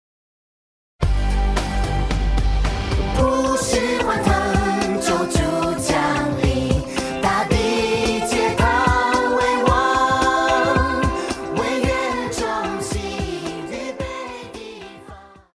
Christian
Pop chorus,Children Voice
Band
Christmas Carols,Hymn,POP,Christian Music
Voice with accompaniment